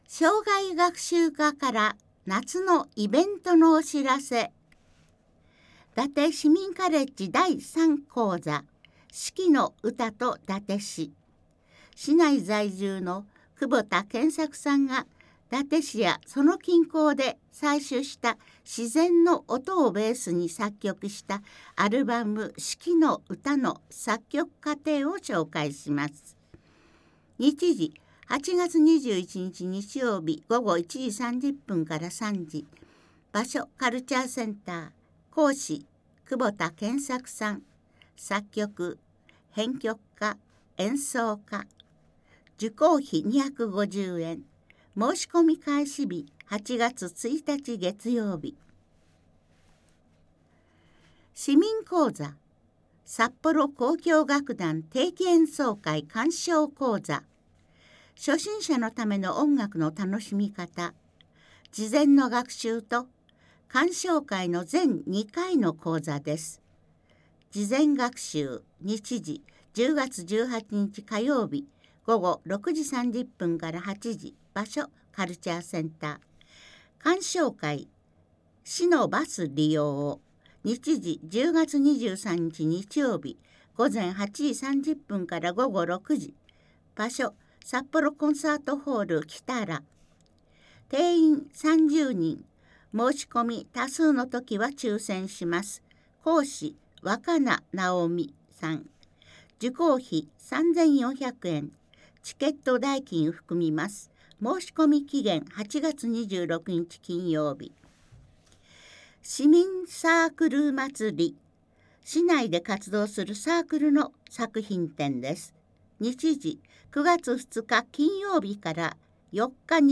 内容をカセットテープに録音し、配布している事業です。
■朗読ボランティア「やまびこ」が音訳しています